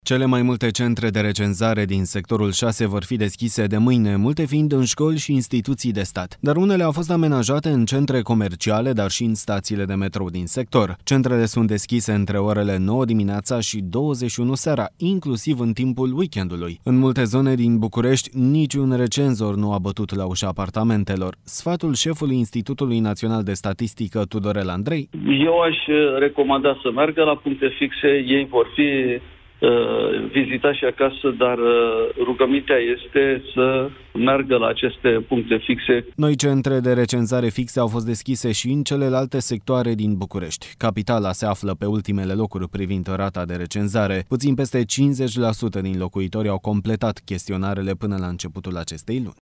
Sfatul șefului Institutului Național de Statistică, Tudorel Andrei: